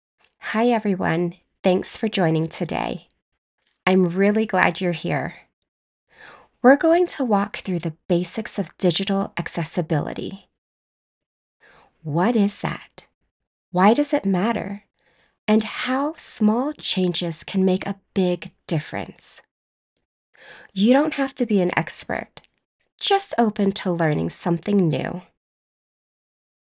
Explainer - Clear, Casual, Supportive.mp3
Middle Aged